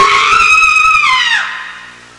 Screaming Woman Sound Effect
Download a high-quality screaming woman sound effect.
screaming-woman.mp3